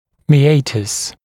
[mɪˈeɪtəs][миˈэйтэс]отверстие, проход